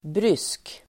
Uttal: [brys:k]